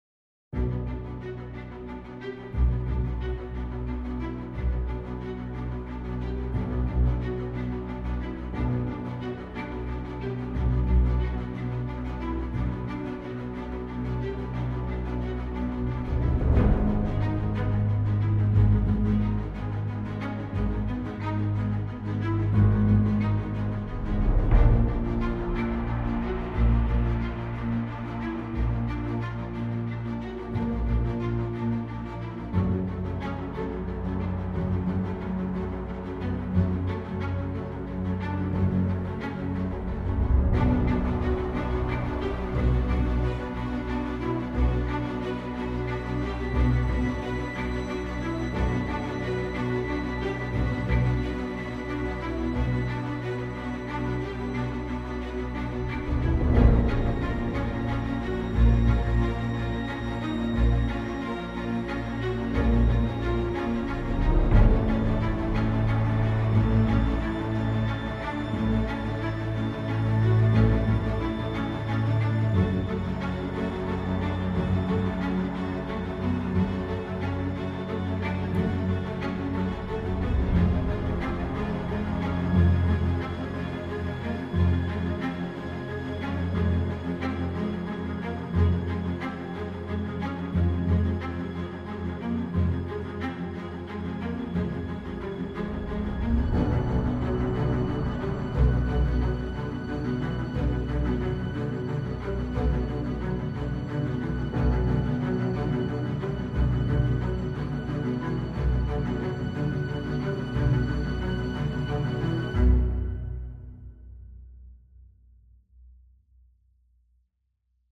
only string rhythm